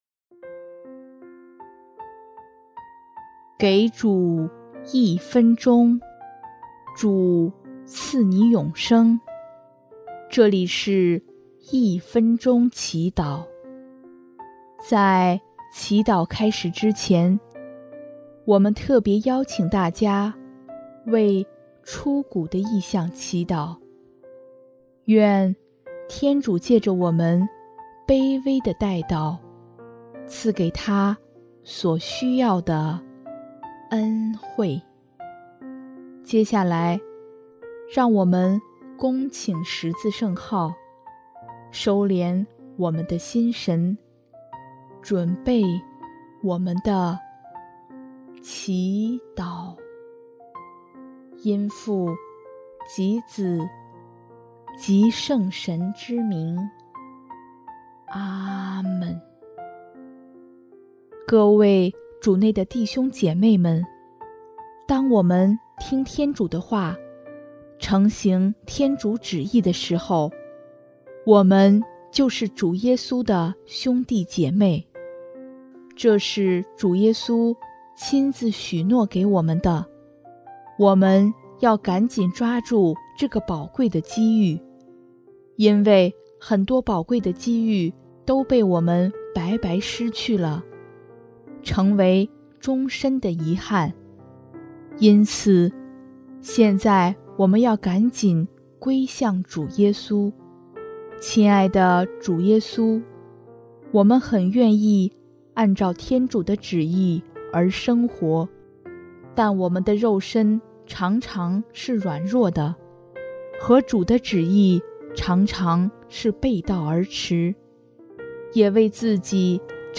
【一分钟祈祷】|6月9日 努力承行天主旨意
音乐： 主日赞歌《凡求的就必得到》（出谷：祈求天主赐予自己平安与爱，感谢天主在自己遭遇这么多事时的扶持）